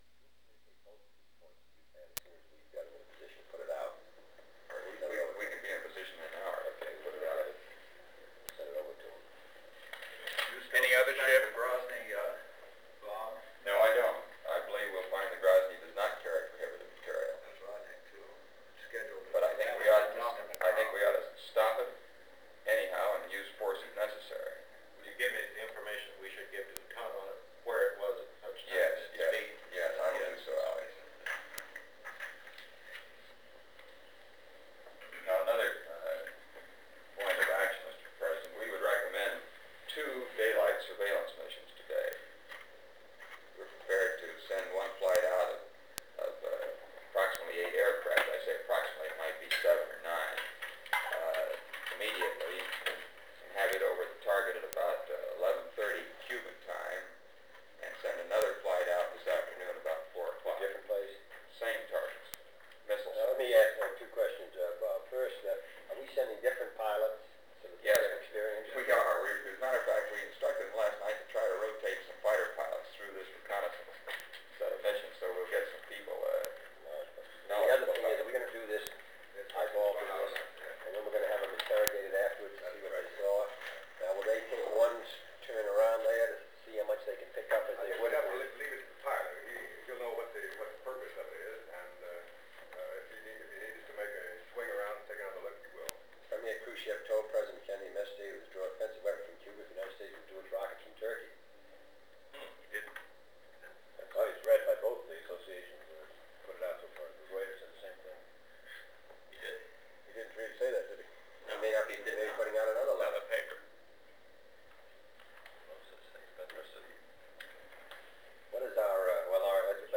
Executive Committee Meeting of the National Security Council on the Cuban Missile Crisis
Secret White House Tapes | John F. Kennedy Presidency